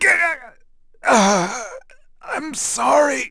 Zafir-Vox_Dead.wav